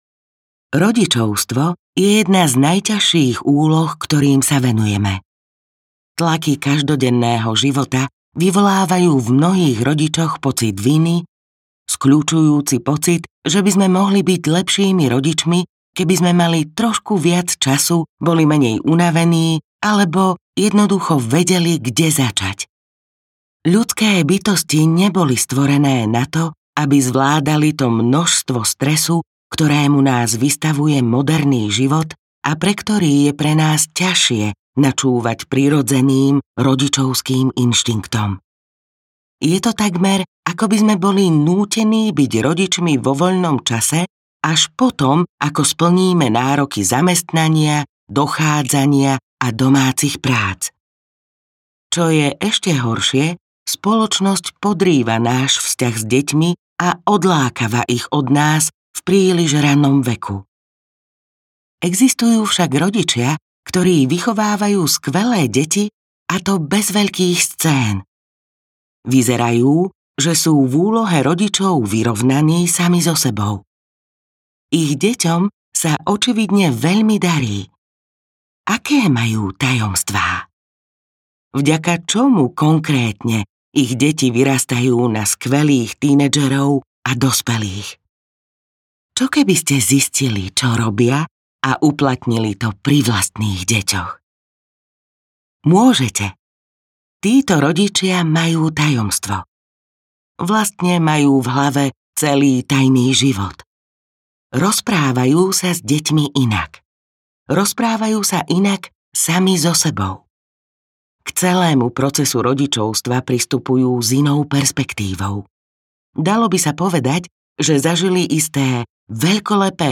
Audiokniha AHA! Rodičovstvo - Laura Markham | ProgresGuru